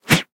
hand throw.ogg